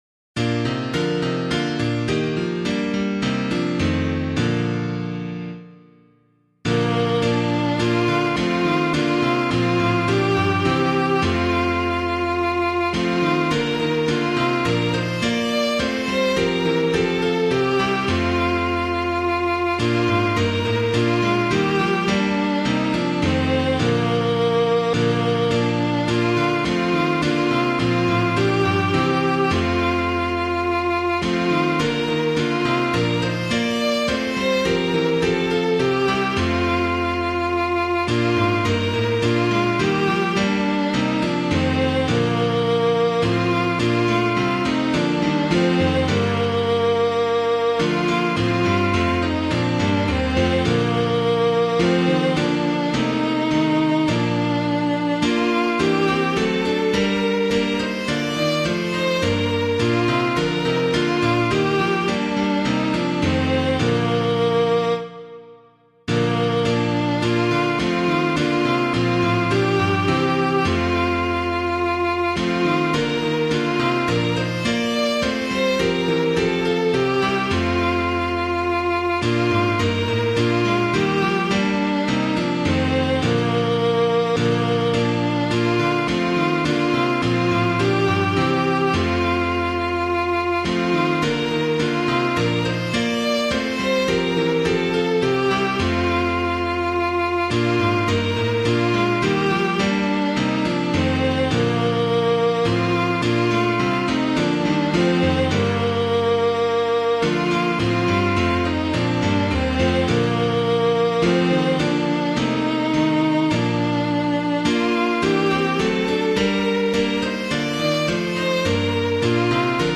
piano 🎤
Wake O Wake the Night Is Dying [Hagan - WACHET AUF] - piano [CWB2].mp3